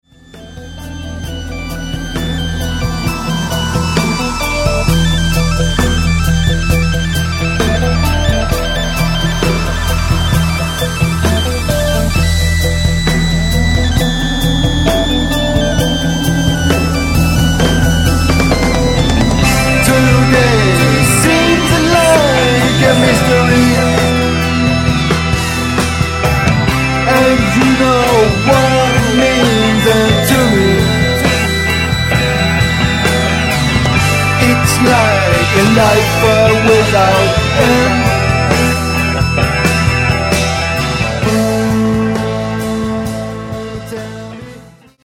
recorded at Colors Music Studio, Neuchâtel, Switzerland